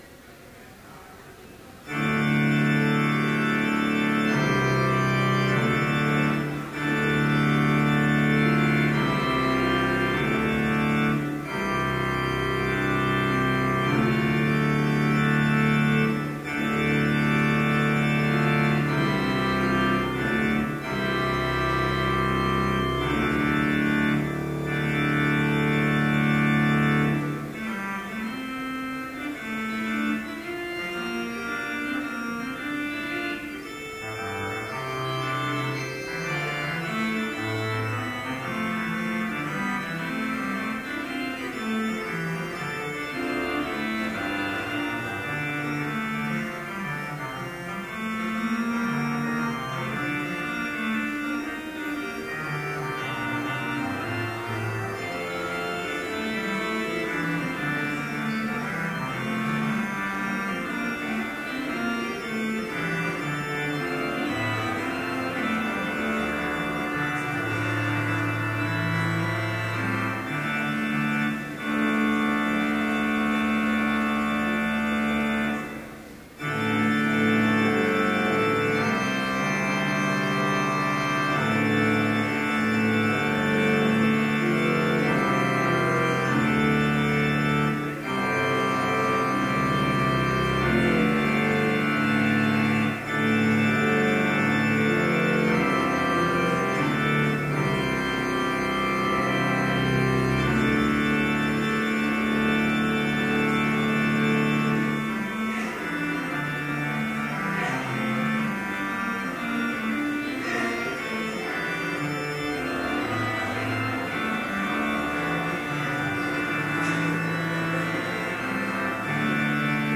Complete service audio for Chapel - April 5, 2013